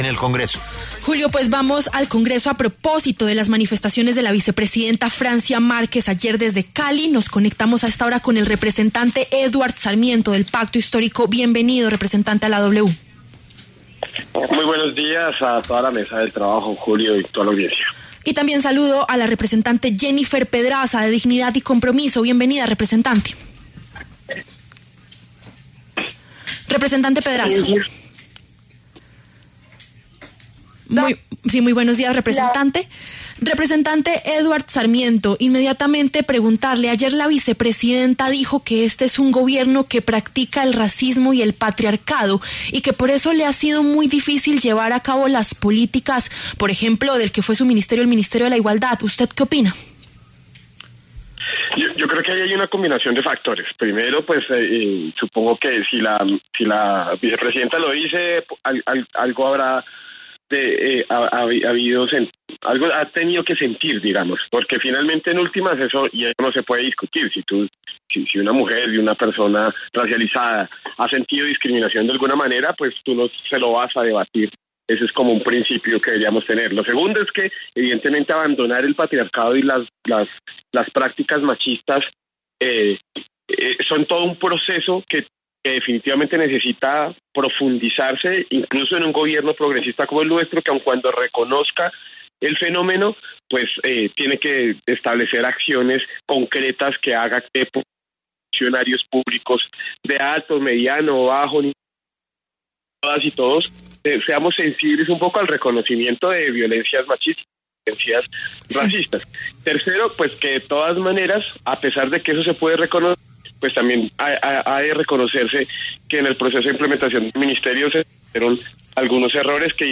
Los representantes Jennifer Pedraza, de Dignidad y Compromiso, y Eduard Sarmiento, del Pacto Histórico, pasaron por los micrófonos de La W.